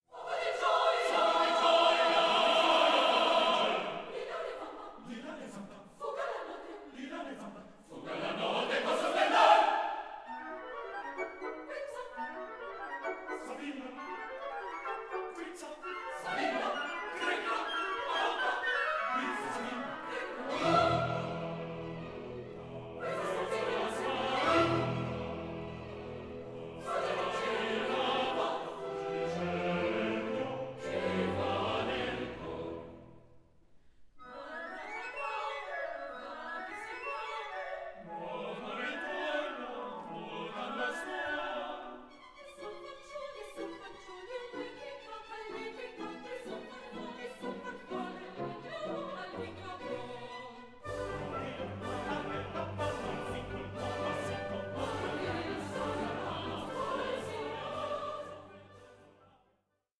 ambitious studio recording
American soprano
Austrian tenor
American baritone